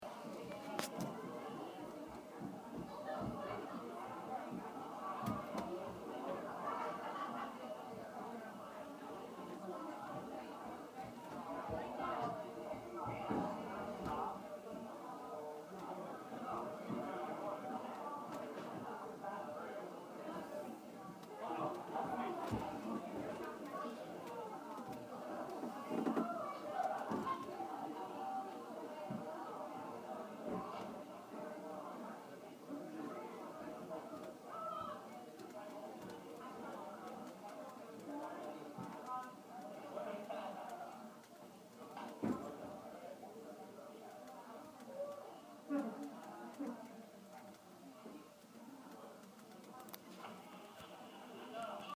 Field Recording #5
Booth of the Spiegel Theater – 3/2 – 8:59 PM 2. The static from the speaker, voices from the house during intermission, the creaking of the booth itself whenever someone moves